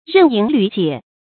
刃迎縷解 注音： ㄖㄣˋ ㄧㄥˊ ㄌㄩˇ ㄐㄧㄝ ˇ 讀音讀法： 意思解釋： 比喻順利解決。